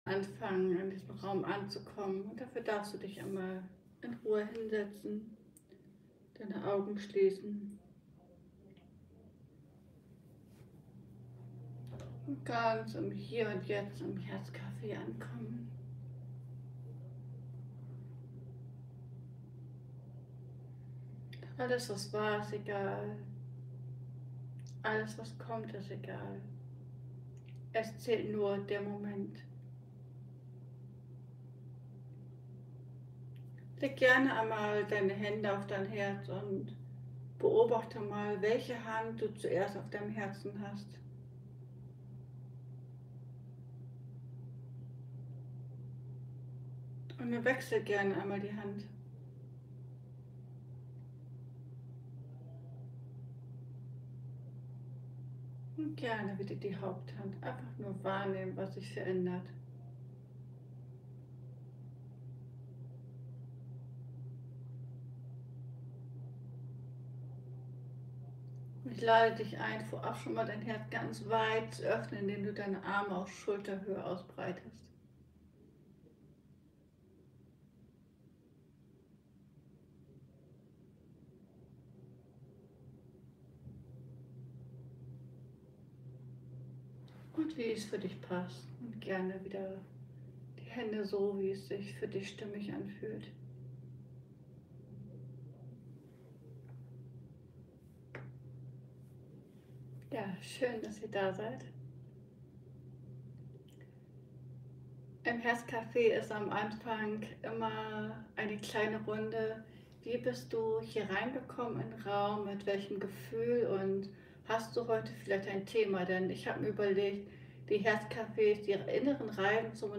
Liveübertragung Herz Cafe ~ Ankommen lassen Podcast